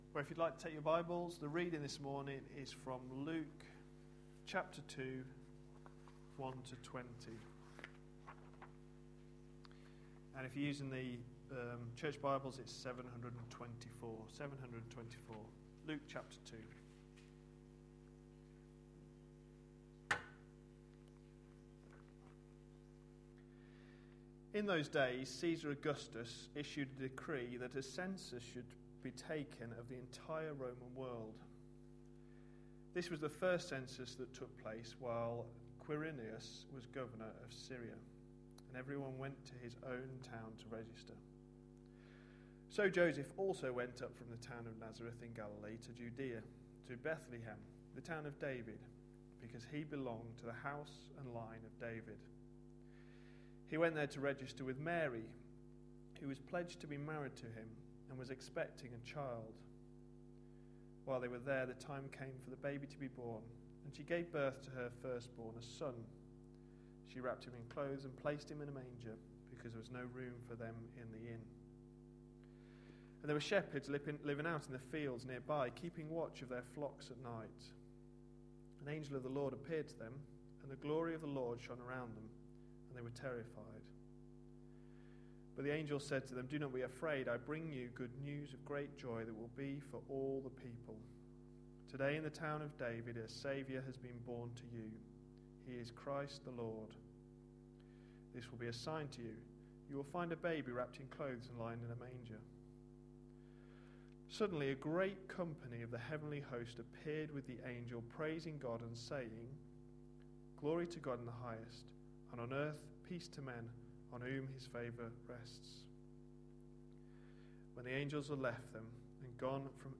A sermon preached on 18th December, 2011.